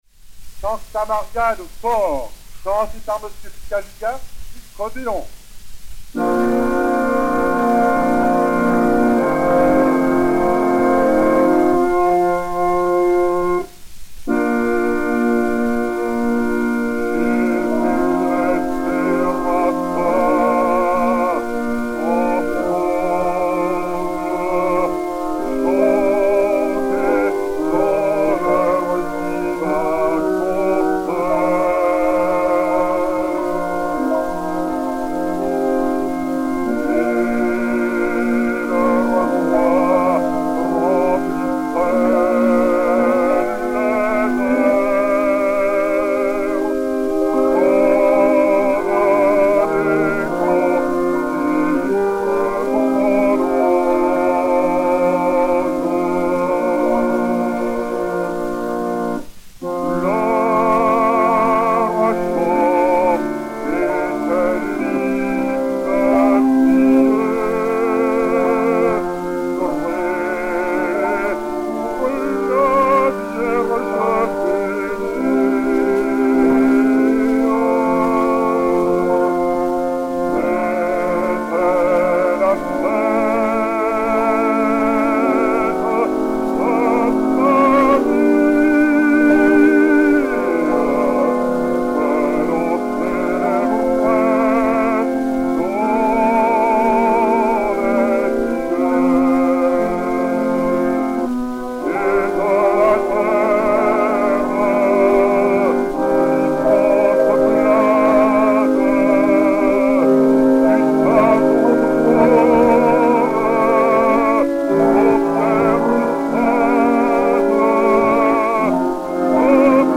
Hymne
orgue